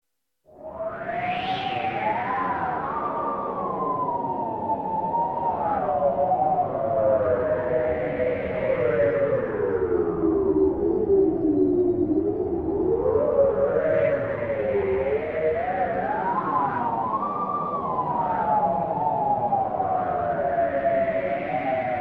Звуки зимнего леса
Шум зимнего леса в порывистом ветре